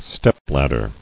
[step·lad·der]